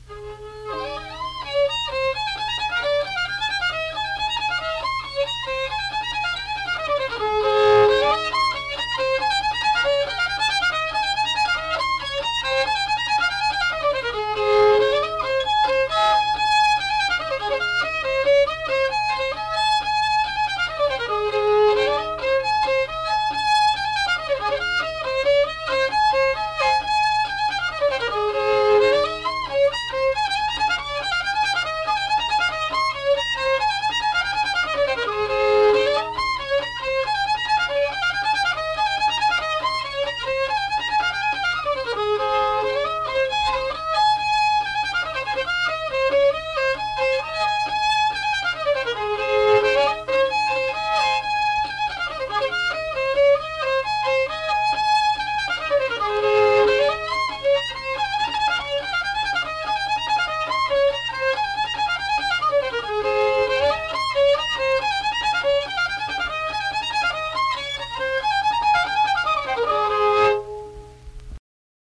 Polka
Unaccompanied fiddle music and accompanied (guitar) vocal music performance
Instrumental Music
Fiddle